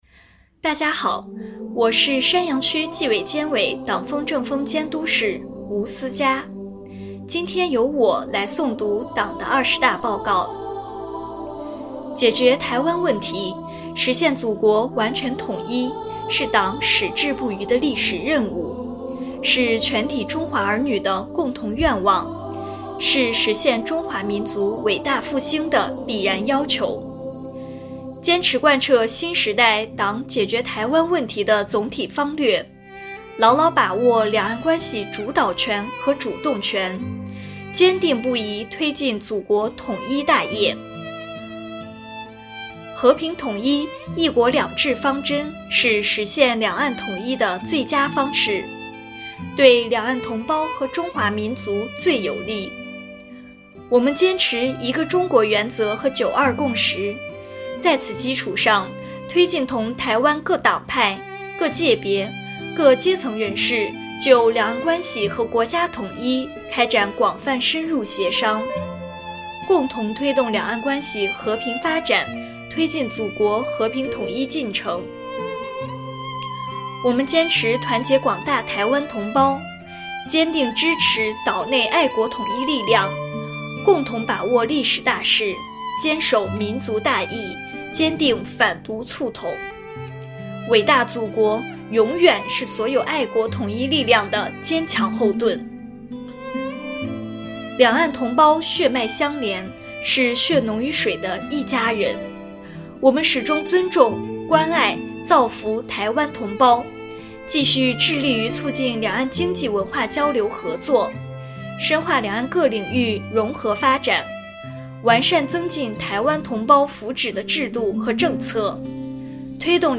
诵读内容